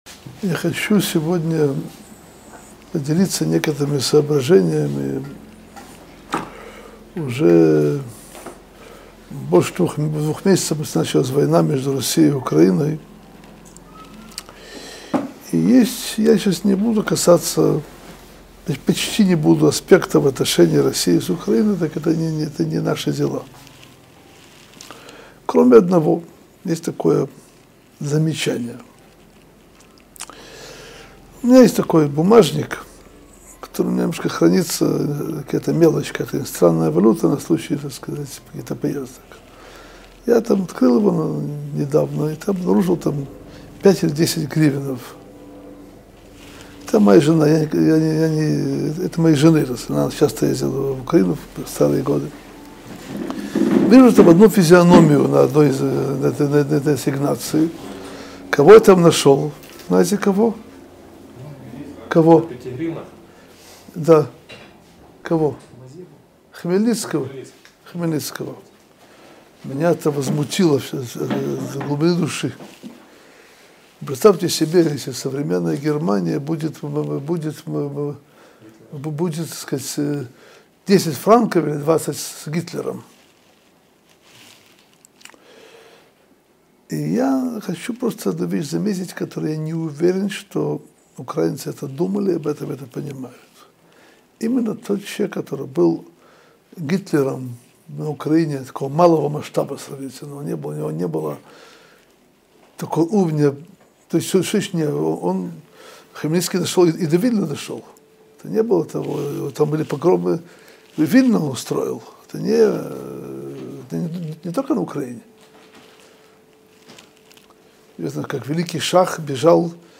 Содержание урока: Почему изображение Б.Хмельницкого присутствует на деньгах Украины?